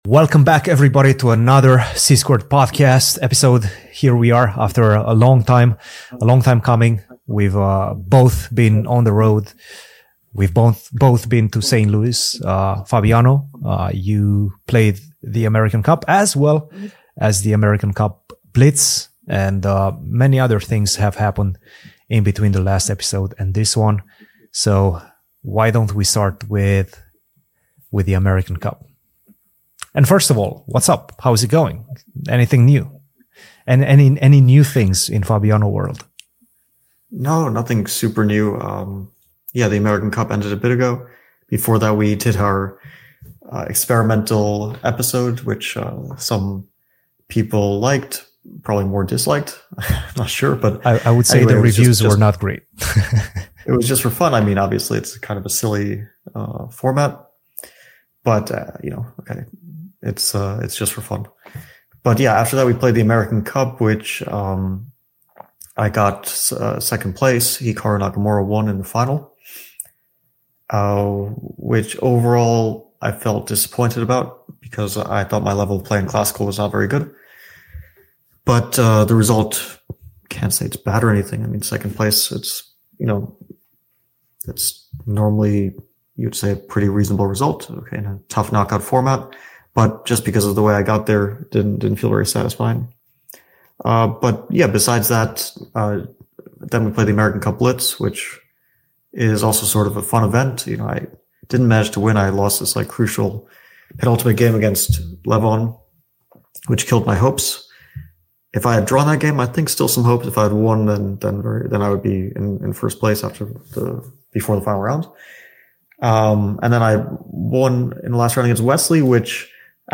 The C-Squared Podcast is an in depth weekly discussion about the chess world with your hosts